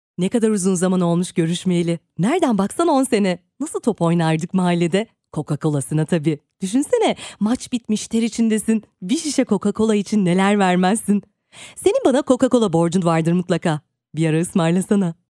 Turkish female voice over talent, turkish e-learning female voice, turkish female narrator
Sprechprobe: Werbung (Muttersprache):